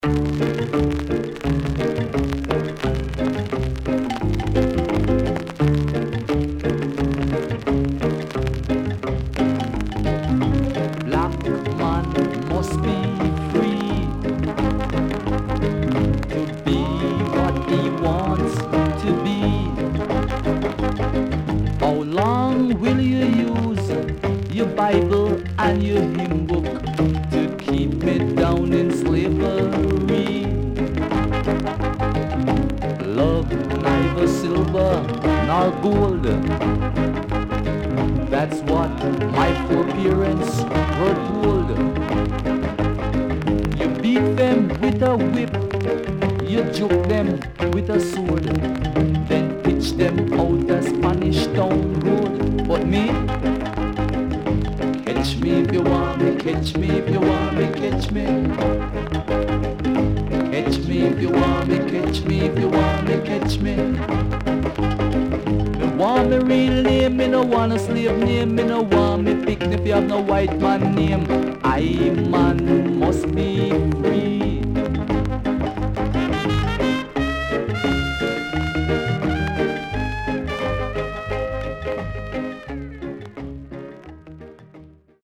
SIDE A:全体的にチリノイズ、プチパチノイズ入ります。